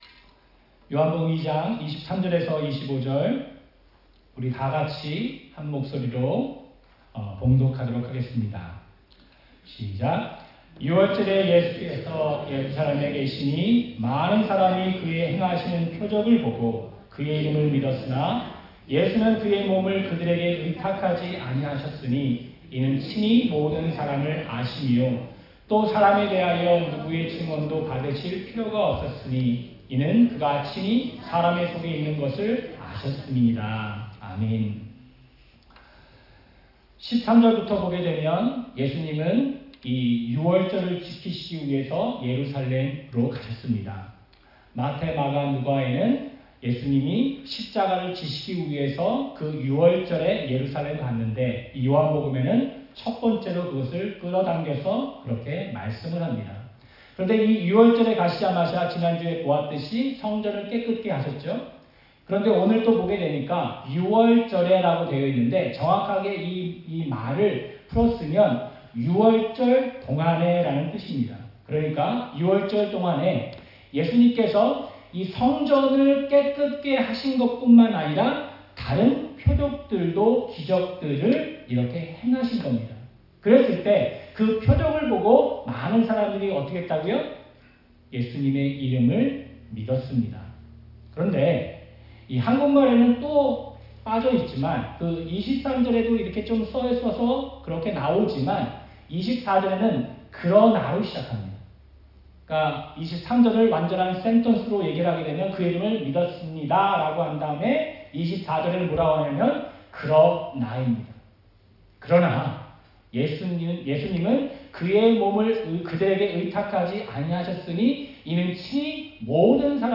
주일 설교
11월-5일-주일-설교.mp3